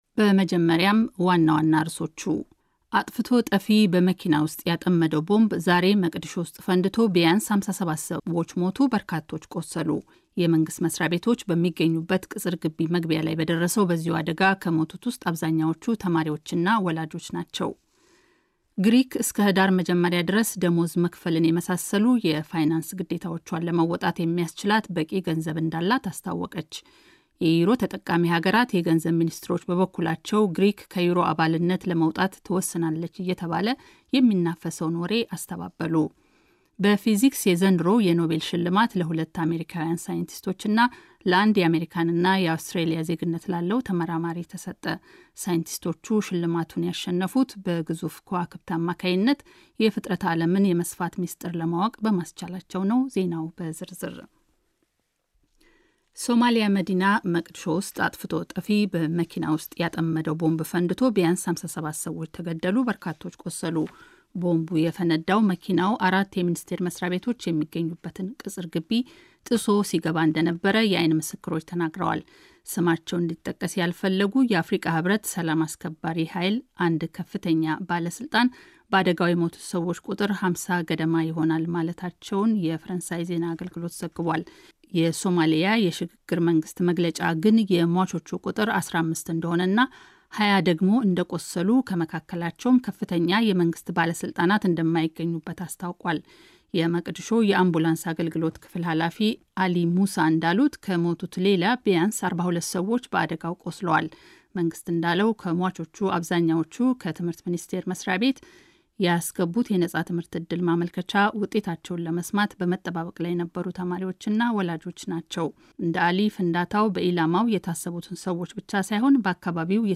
ጀርመን ራዲዮ ዜናዎች – Oct. 4, 2011